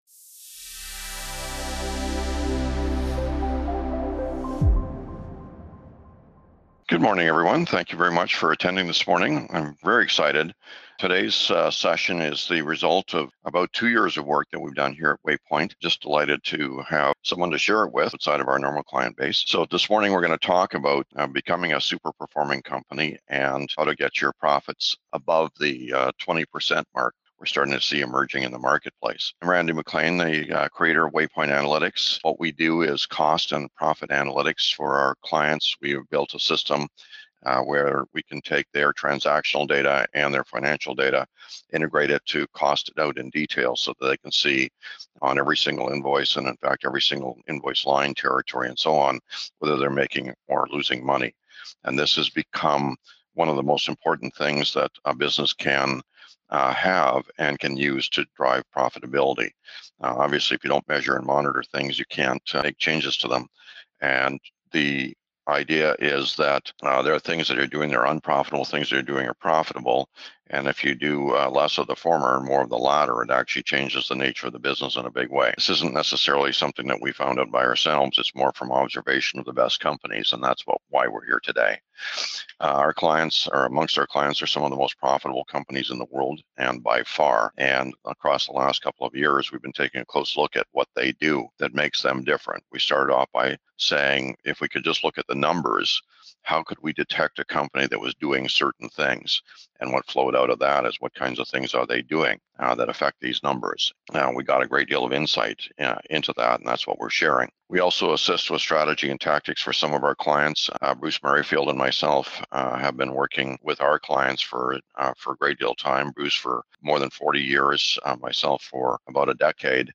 profit20webinar.mp3